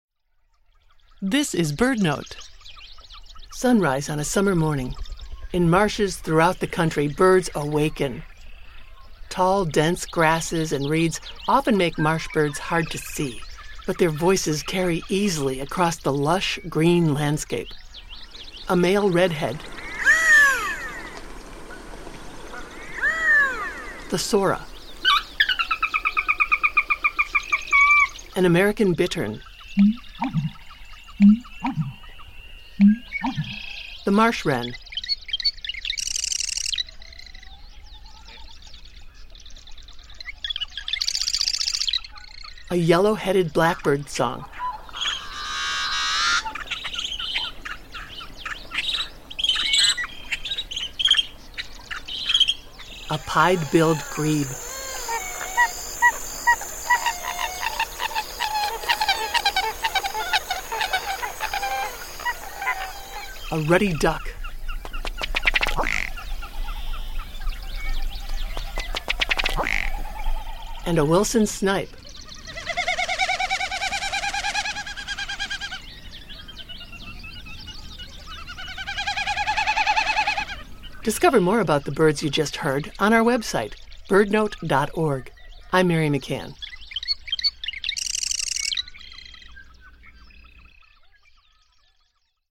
In marshes across the country, birds awaken on a summer morning. Tall dense grasses and reeds often make marsh birds hard to see, but their voices carry easily across the lush, green landscape. You can hear birds like the Redhead, the Sora, the American Bittern, the Ruddy Duck, this Yellow-headed Blackbird, and many more.